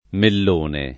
mellone [ mell 1 ne ]